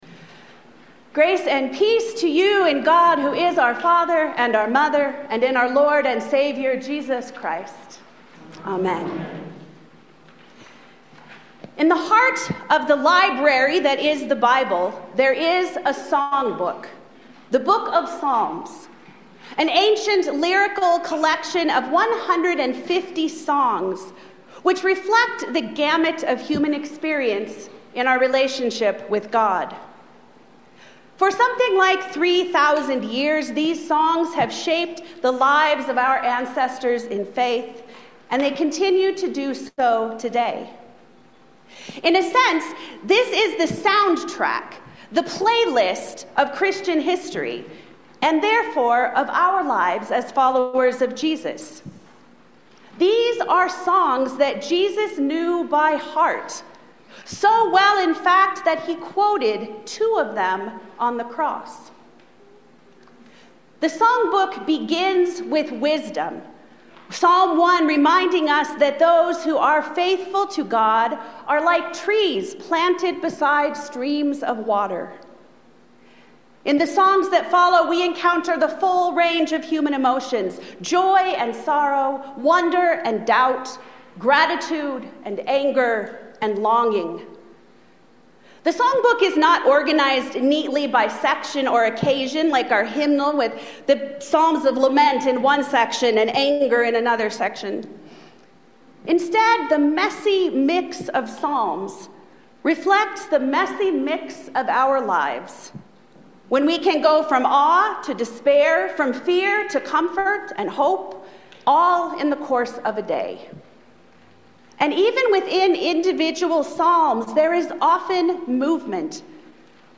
Passage: Jeremiah 29:4-14 Service Type: Sunday Morning